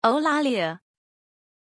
Aussprache von Eulalia
pronunciation-eulalia-zh.mp3